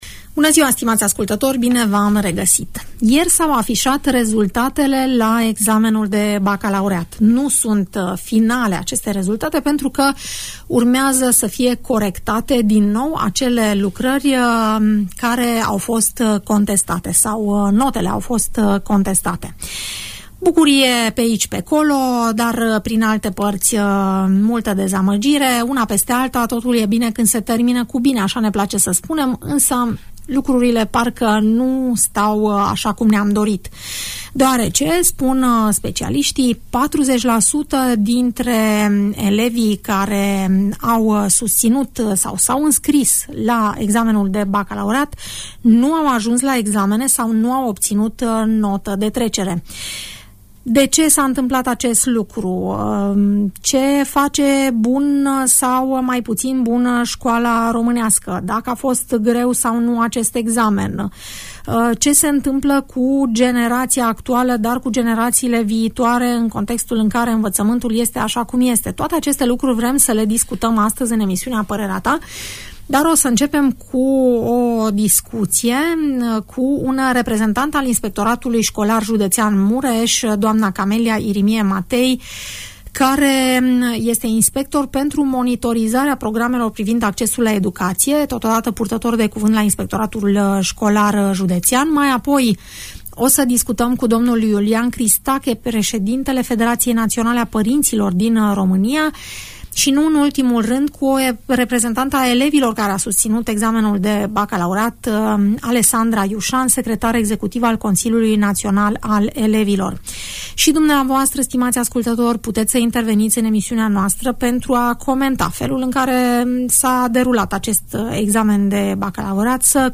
Care sunt rezultatele obținute de candidații la examenul de bacalaureat din acest an și cum sunt interpretate de către profesori, părinți sau elevi, discutăm la Radio Tg Mureș, în emisiunea „Părerea ta”